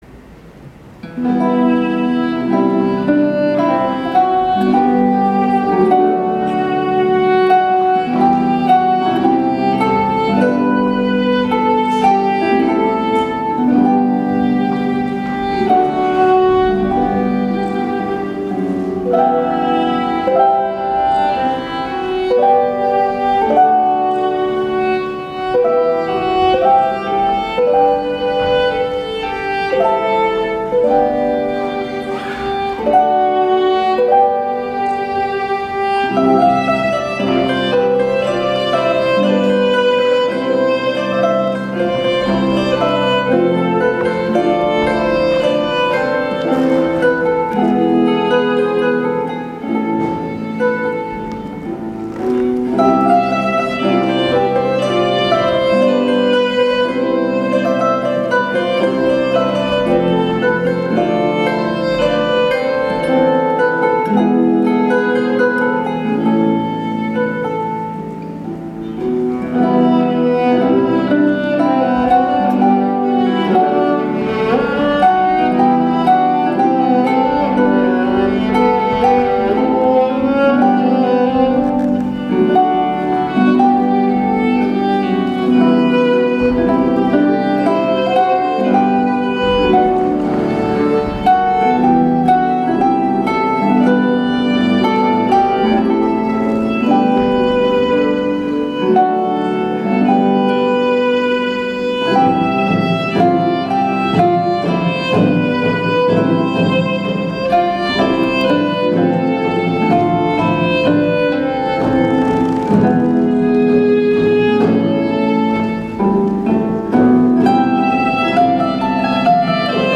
Klavier) / Tschaikowsy-Saal, Hamburg 9.9.2023